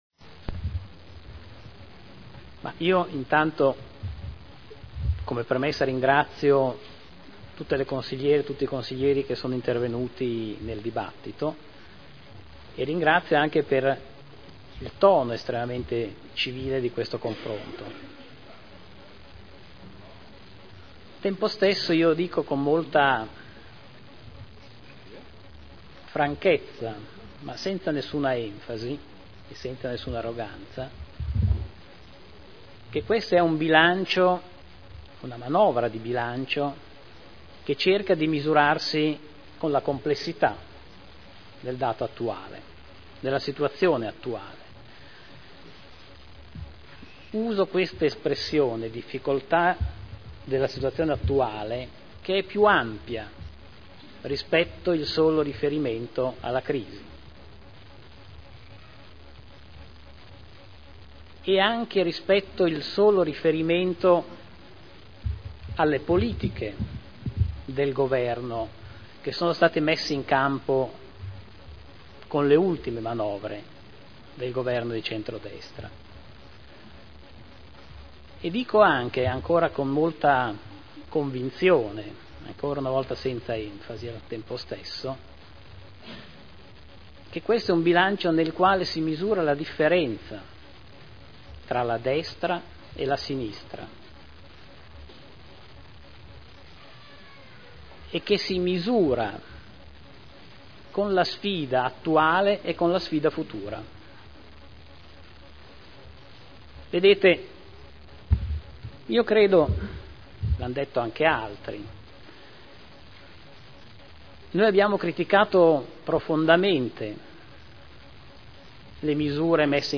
Alvaro Colombo — Sito Audio Consiglio Comunale